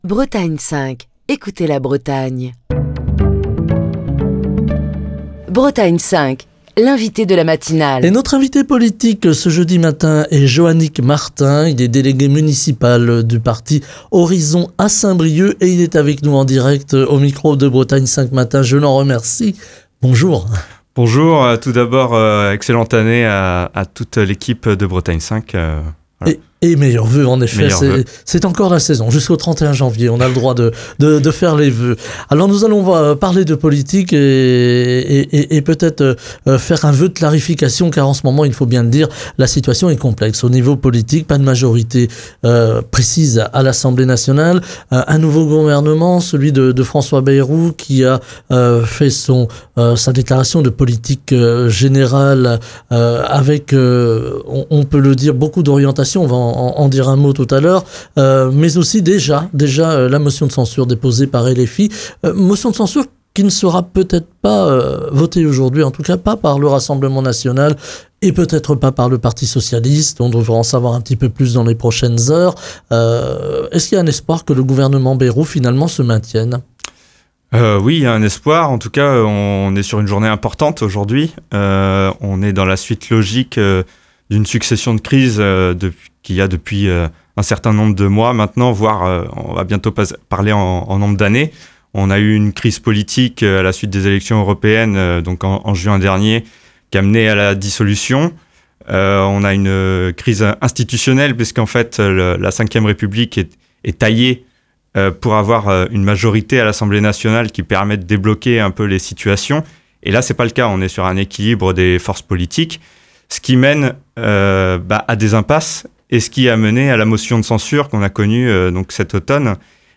était l'invité politique de la matinale de Bretagne 5 ce jeudi.